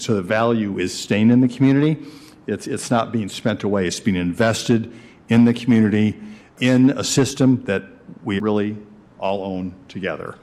Now, nearly a third of it is money they are spending on streets and water projects that Anderson says will make the community a better place.